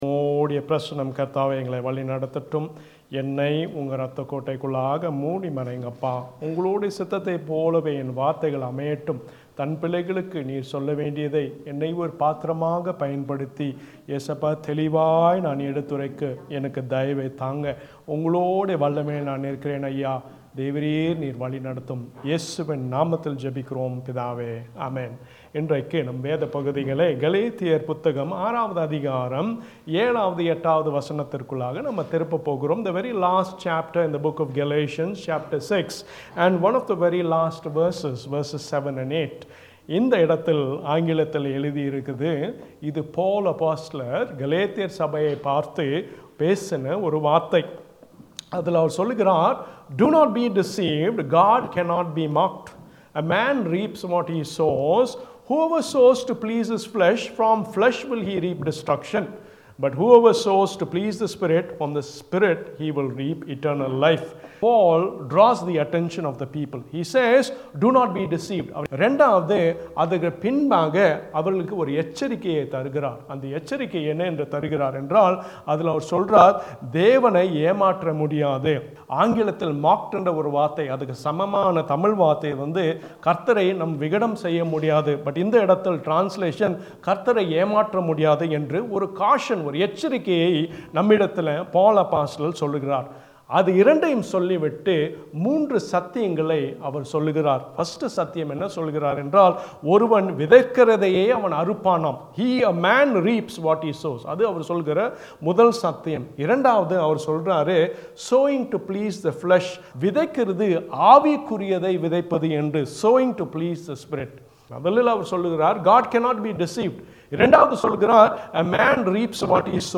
Sermon-17Nov2024.mp3